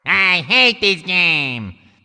One of Waluigi's voice clips in Mario Party 6